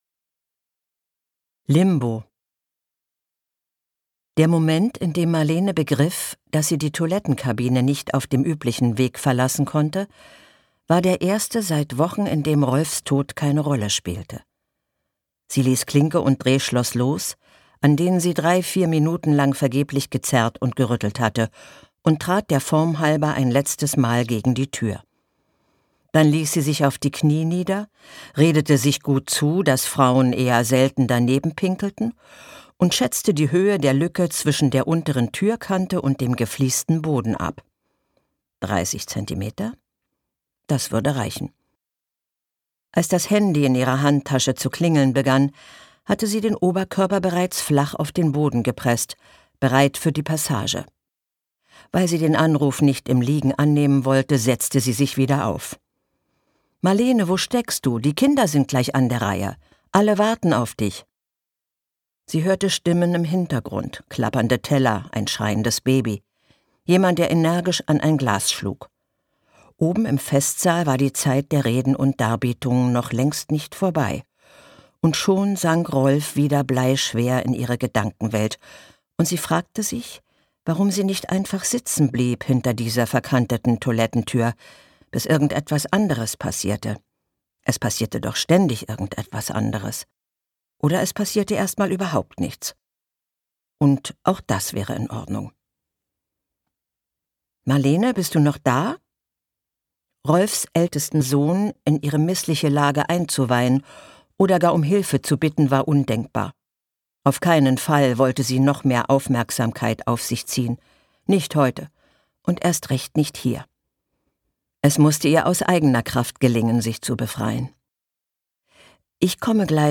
Ruth Reinecke (Sprecher)
2025 | Ungekürzte Lesung
Ungekürzte Lesung mit Ruth Reinecke